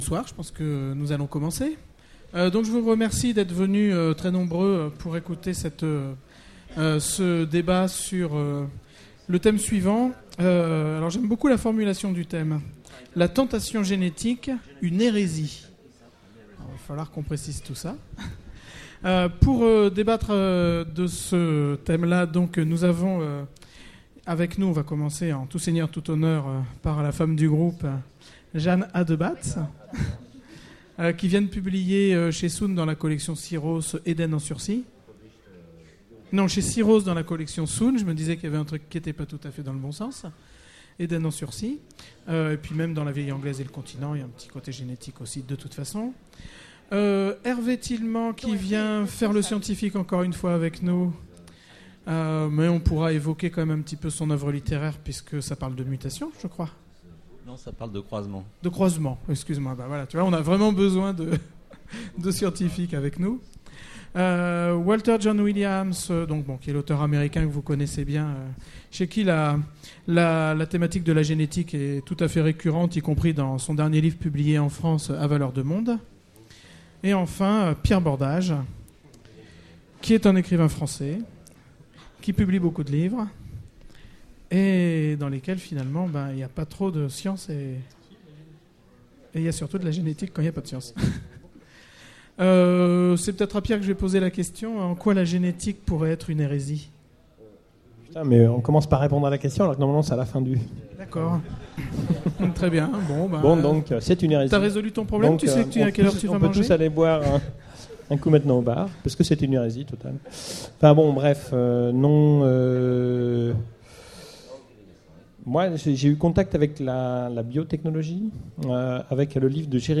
Utopiales 2009 : Conférence La tentation génétique, une hérésie ?